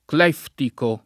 cleftico [ kl $ ftiko ] agg.; pl. m. ‑ci